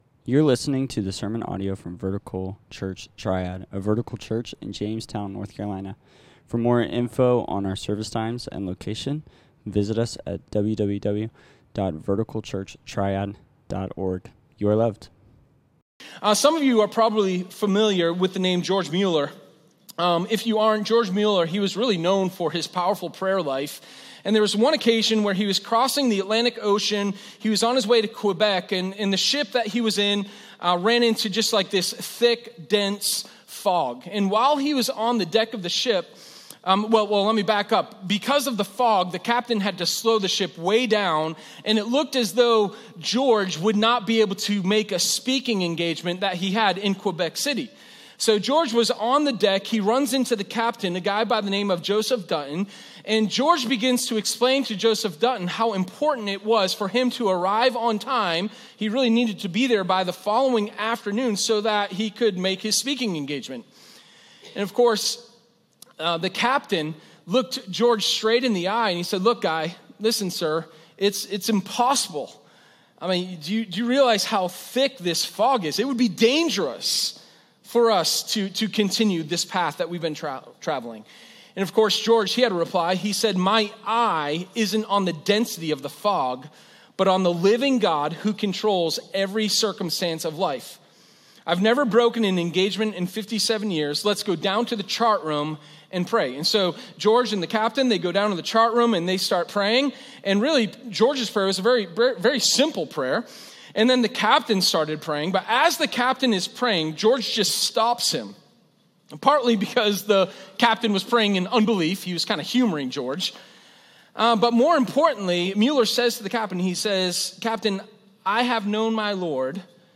Sermon0626_A-Call-to-Pray.m4a